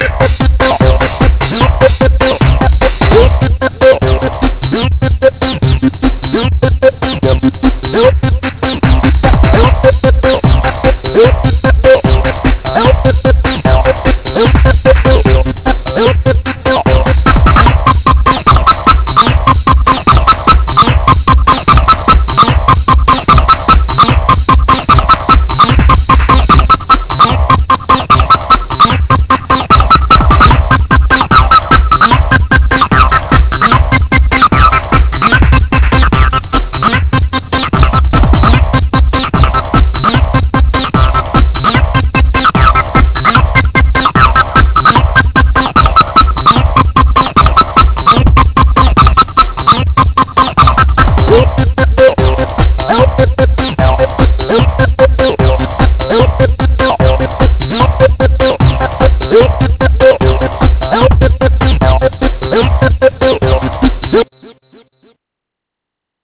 Jungle, 150 BPM